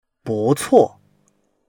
bu4cuo4.mp3